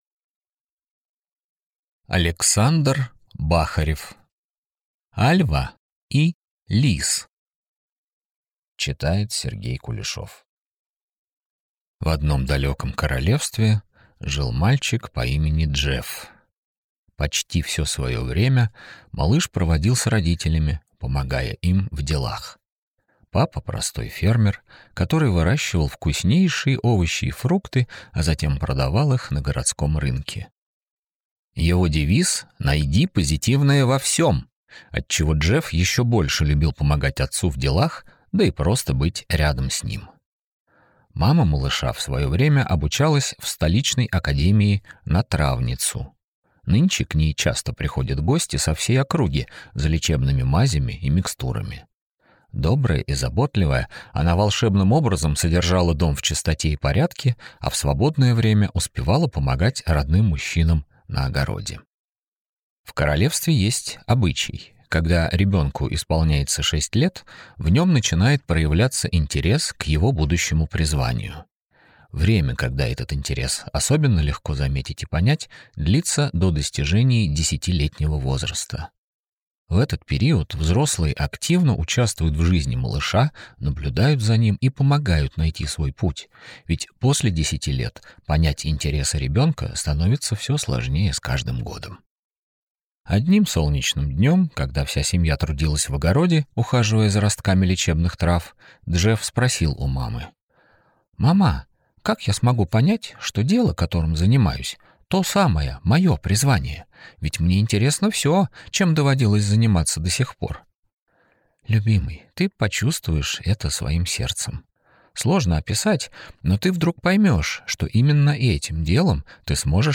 Аудиокнига Альва и лис | Библиотека аудиокниг